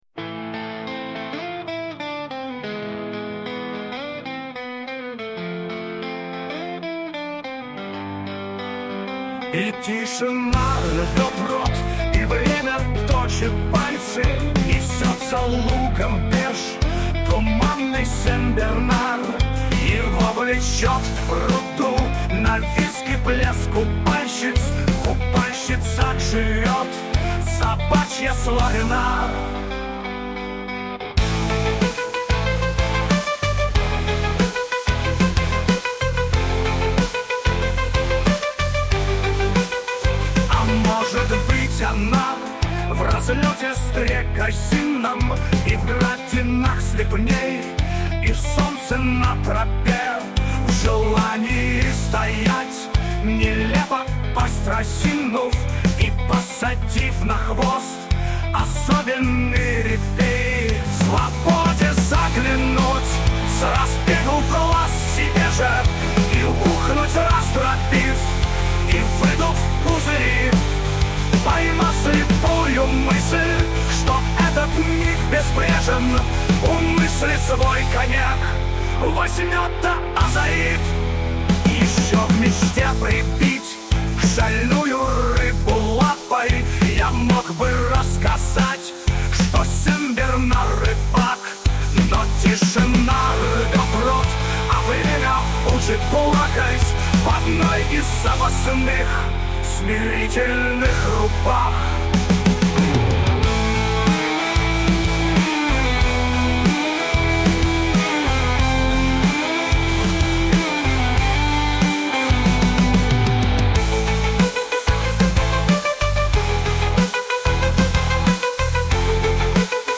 Стихи, понятно, древние мои, музыка и голос, понятно, нейросетевые.
Нейросеть где-то понимает "ё" в простой "е", а где-то нет.